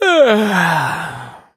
sandy_start_vo_01.ogg